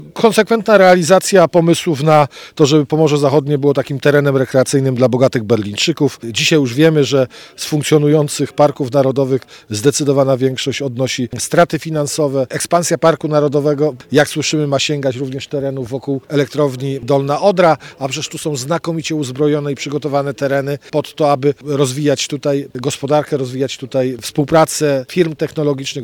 Jednym z najgłośniejszych głosów sprzeciwu wobec planowanego parku jest europoseł Prawa i Sprawiedliwości, Joachim Brudziński. W swojej wypowiedzi dla Twojego radia skrytykował nie tylko samą ideę nowego parku, ale także sposób myślenia o przyszłości Pomorza Zachodniego: